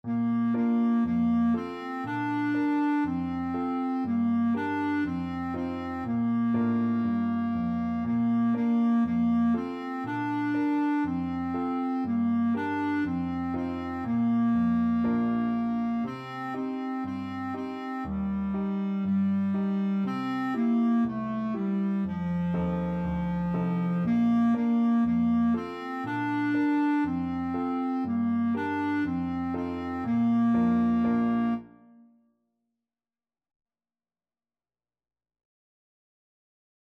Clarinet
Bb major (Sounding Pitch) C major (Clarinet in Bb) (View more Bb major Music for Clarinet )
4/4 (View more 4/4 Music)
Allegro (View more music marked Allegro)
F4-D5